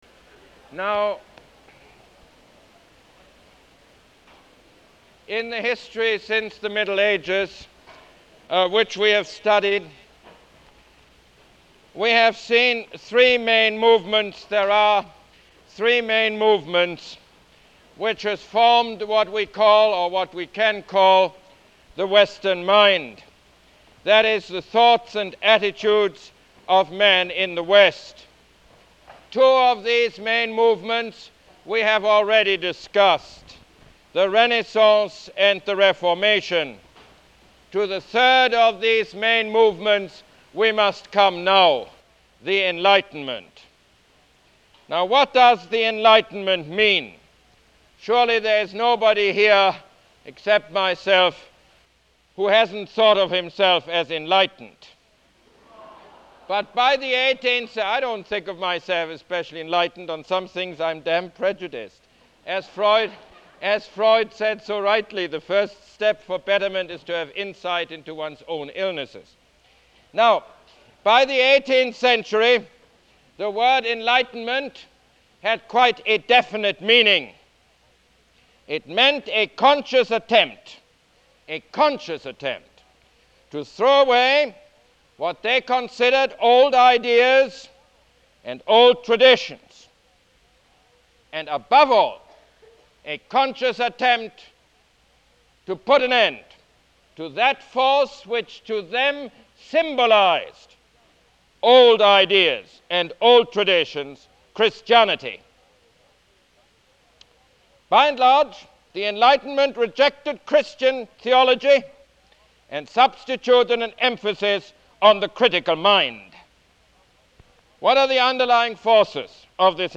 Lecture #20 - The Rise of Science